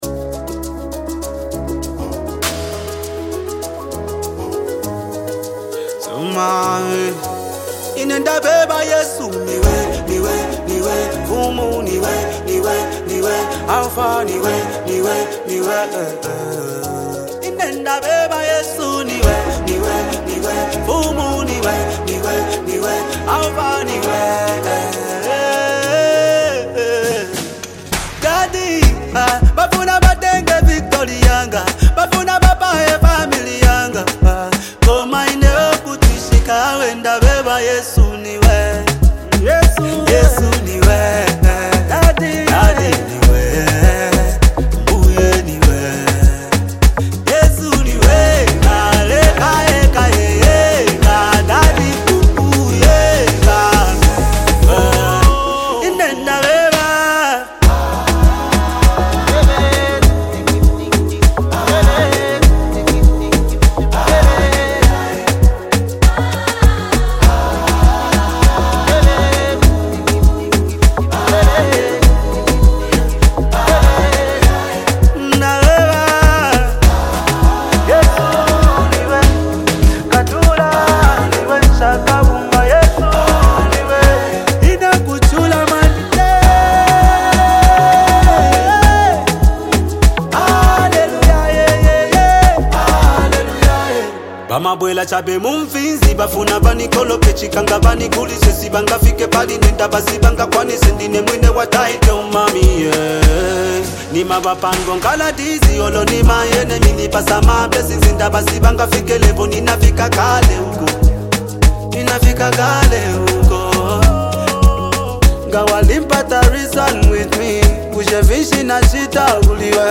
" a spiritually uplifting song.
merging smooth instrumentals with a rich and melodic sound.
passionate vocals